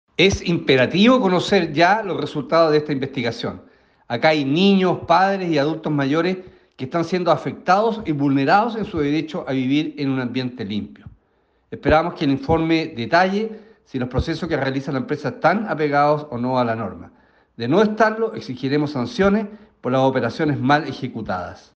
Por su parte el diputado Alejandro Santana, quien ofició a las entidades para realizar una fiscalización inmediata, apoyó los reclamos de los habitantes de estas localidades y demandó que pronto haya un pronunciamiento de estos servicios.